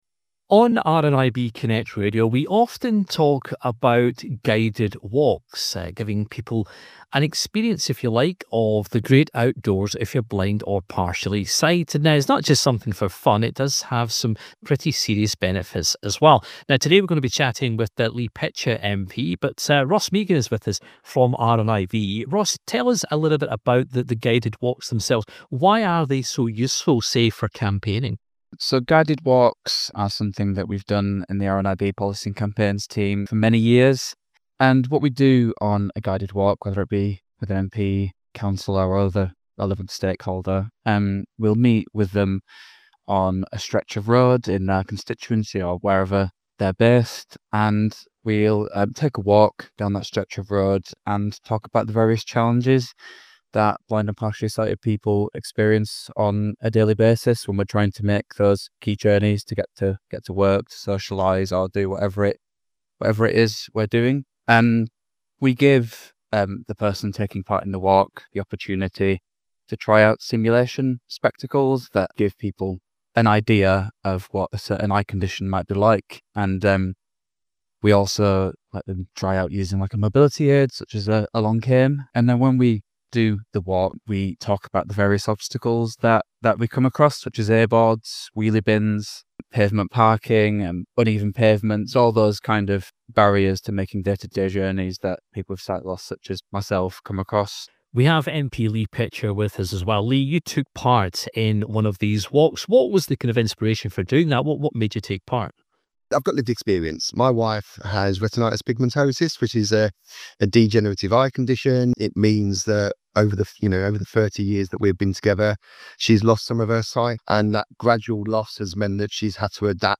spoke to Lee Pitcher MP, about a blind walk he took and what he learned from the experience.